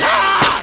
Ooff2.wav